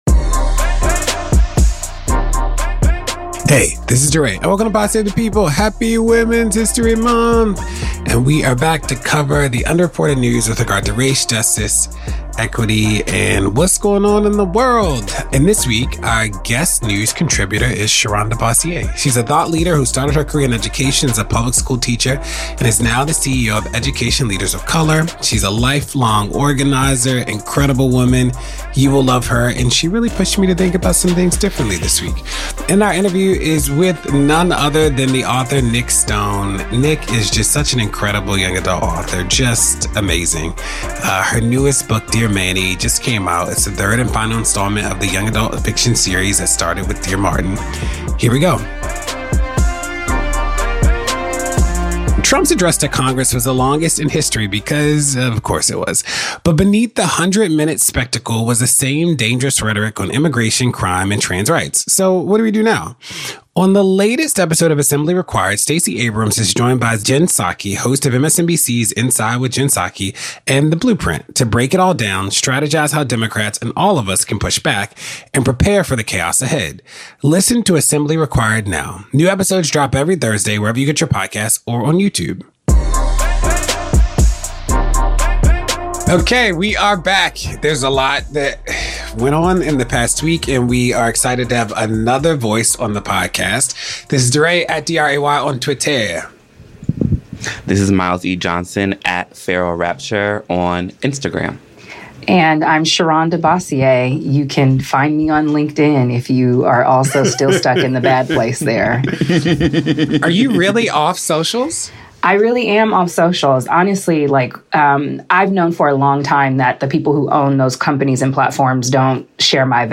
The dark history of retinol, the causal myth of bacterial vaginosis, and Abel Selaocoe crosses time and cultures in new album. DeRay interviews Nic Stone about her new book, Dear Manny.